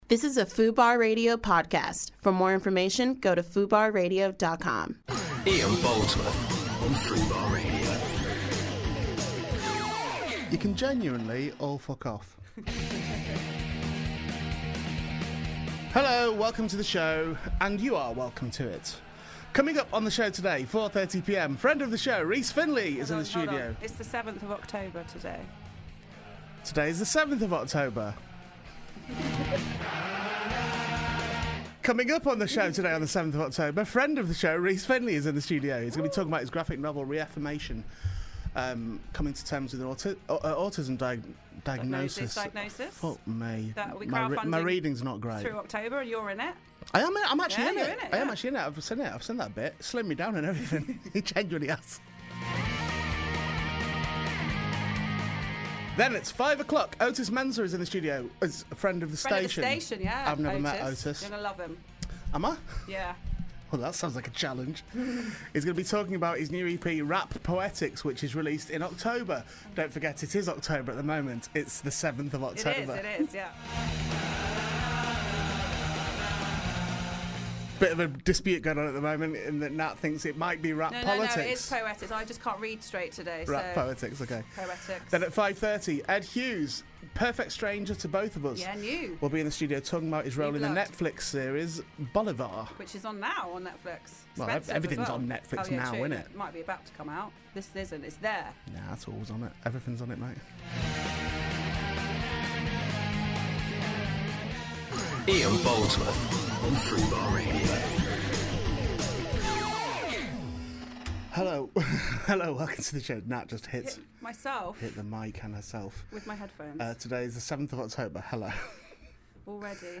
With a string of exclusive, uncensored guest appearances, Ian takes live talk radio to a whole new level.